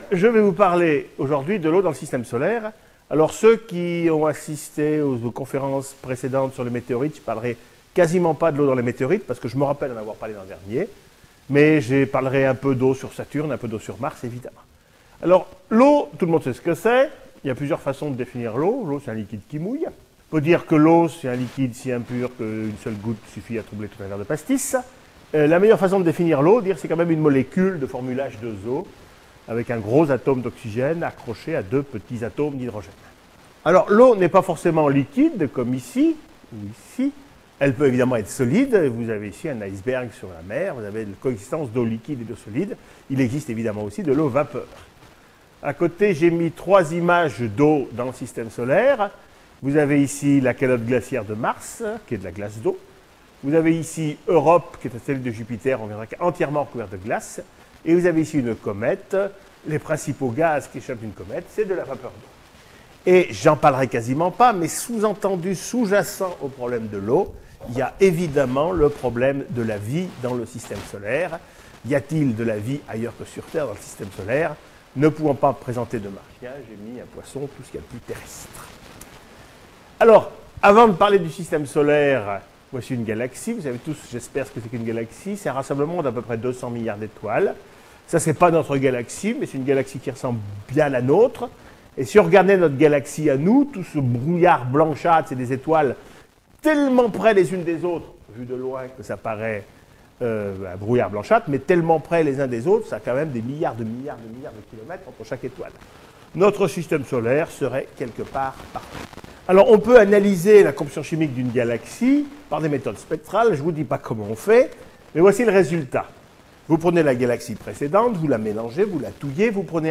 Écouter la conférence L'eau dans le système solaire.